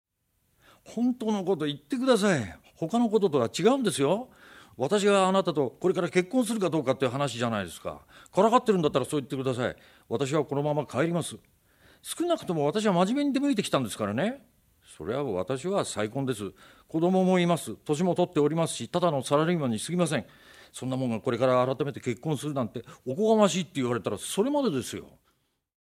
ボイスサンプル
困惑する男性